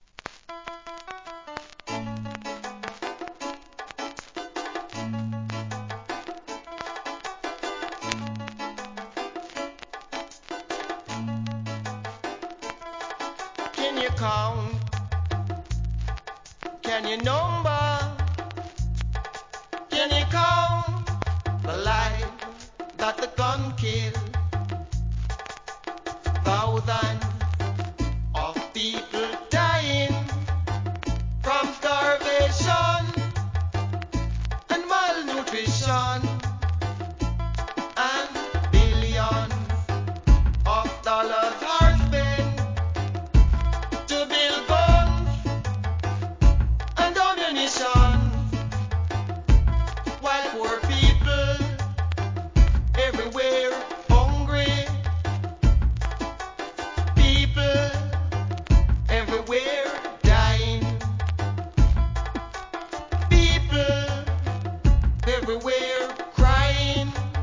REGGAE
ビンギ作品!!